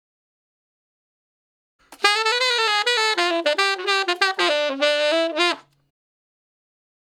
066 Ten Sax Straight (D) 08.wav